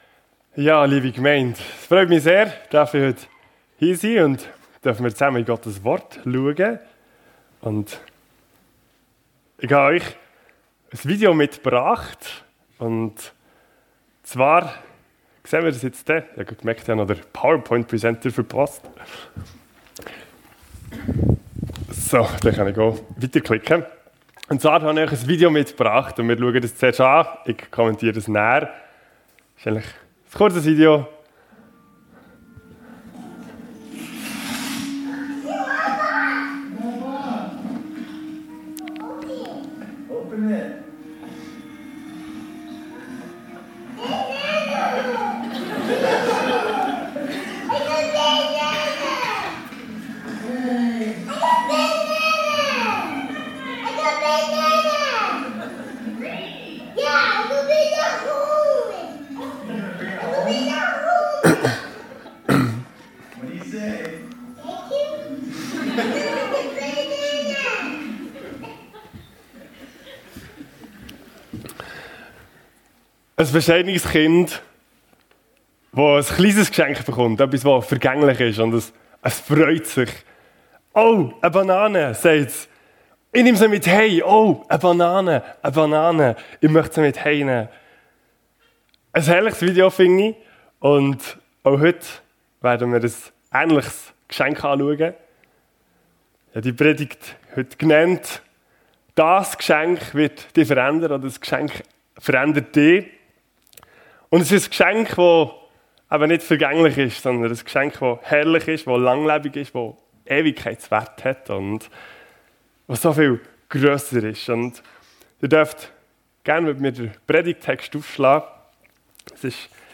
Dieses Geschenk verändert dich ~ FEG Sumiswald - Predigten Podcast